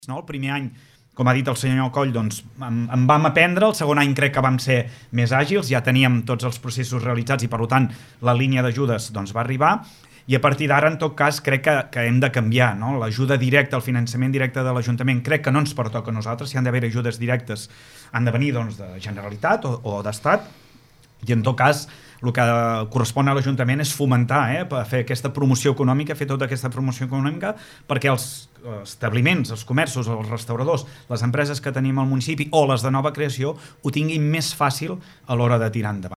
Els dos polítics, l’alcalde per Esquerra Republicana (ERC) Lluís Puig, i el socialista Josep Coll, cap de l’oposició, han analitzat l’actualitat municipal als micròfons de Ràdio Capital de l’Empordà.